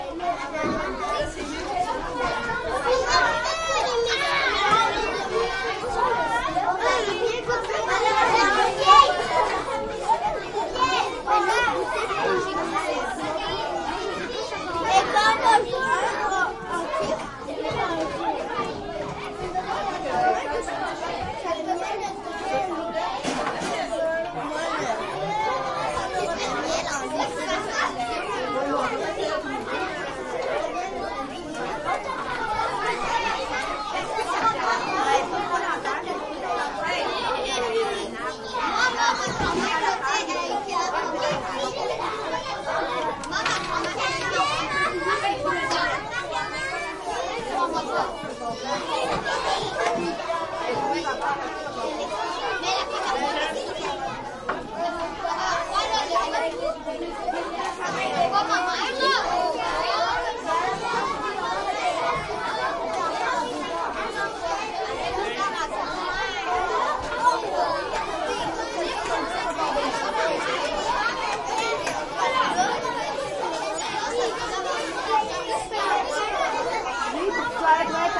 蒙特利尔 " 人群中 小学或幼儿园的孩子们 法语儿童 魁北克的午餐时间 孩子们 活泼好动 沃拉
描述：人群int小学或幼儿园儿童法国enfants quebecois午餐时间孩子活泼活跃的walla
Tag: 儿童 儿童组织 魁北克 小学 活泼 好动 幼儿园 法国 INT 人群 孩子们 学校 沃拉